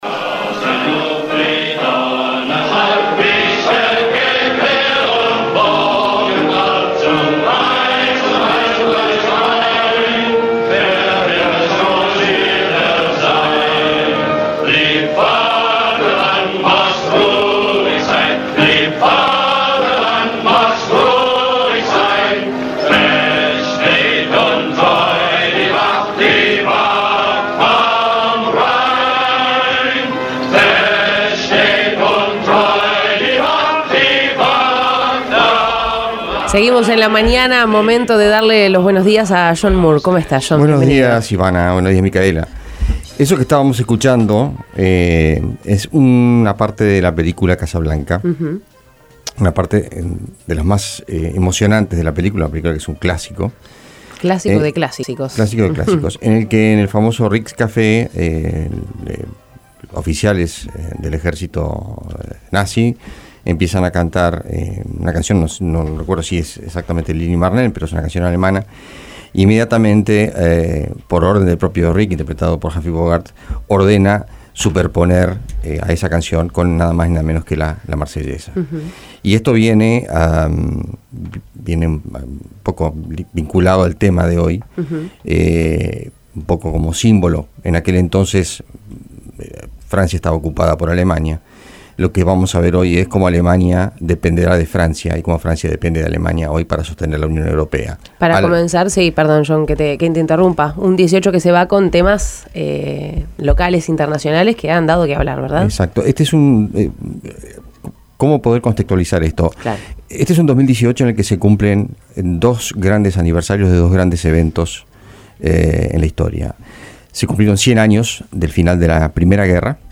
hizo un balance del año en La Mañana de El Espectador, desde donde analizó varios sucesos nacionales e internacionales.